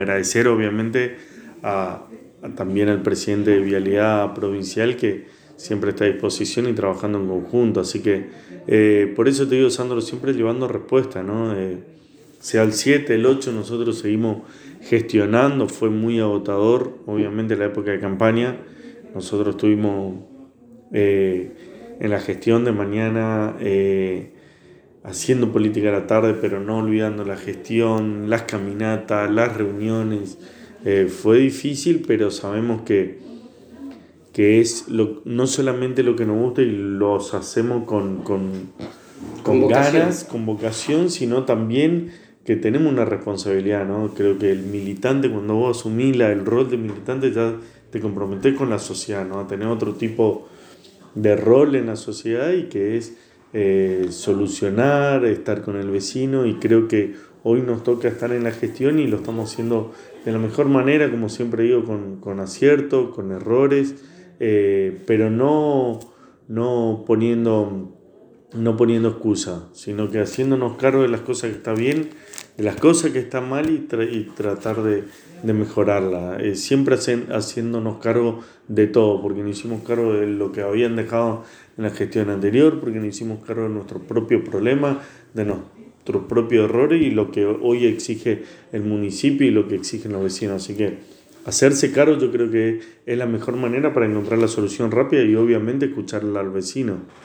En diálogo exclusivo con la ANG el Secretario de Gobierno Gastón Casares manifestó que el apostoleño eligió el domingo seguir creciendo porque con el voto apoyó el trabajo realizado en este tiempo de gestión de María Eugenia Safrán.